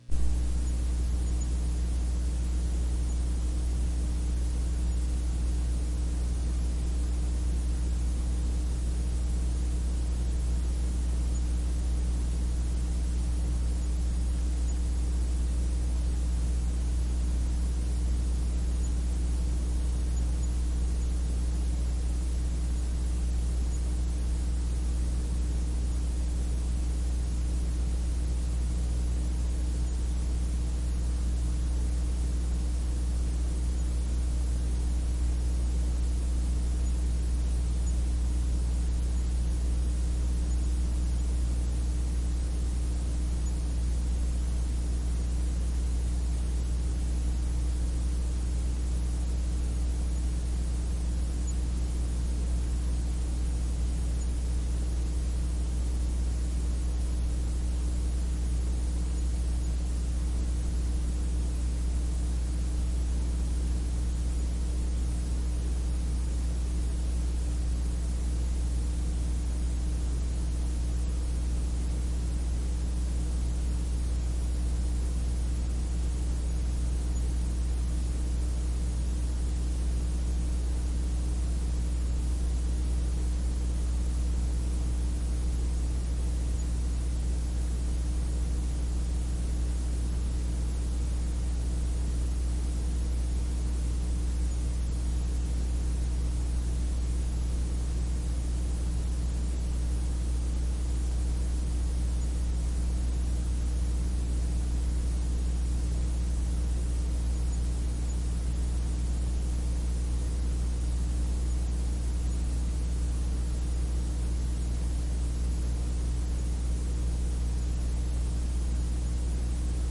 盒式磁带 " 盒式磁带嘶嘶声和接地哼声
描述：盒式磁带嘶嘶声和地面hum.flac
Tag: 嘶嘶声 嗡嗡声 磁带 地面